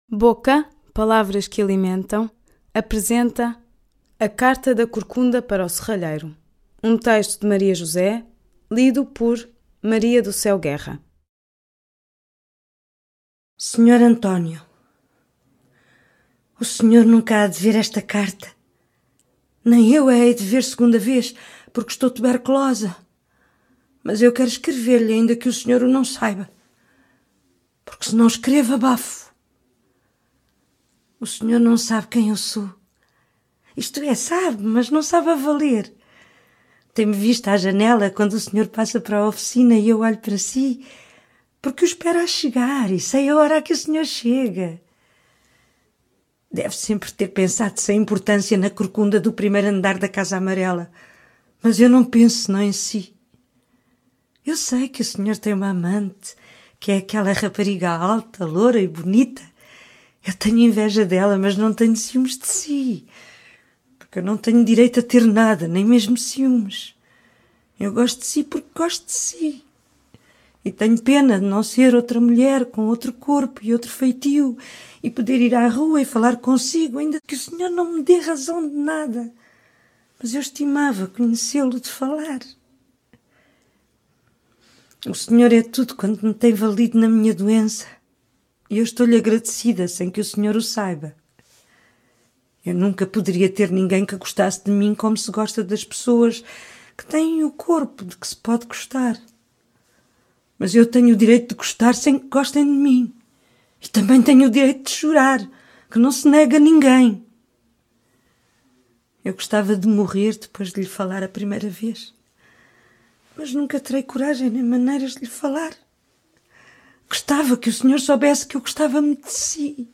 Publicado pela primeira vez em PESSOA POR CONHECER, Carta da Corcunda para o Serralheiro é um texto pungente e na elegância aterradora da sua sinceridade, aperta o coração ouvi-lo lido por Maria do Céu Guerra.
Hoje tenho a honra e o enorme privilégio de poder facultar aos leitores do blog uma gravação dessa leitura.
Maria+Jose+de+Fernando+Pessoa+lido+por+Maria+do+Ceu+Guerra.mp3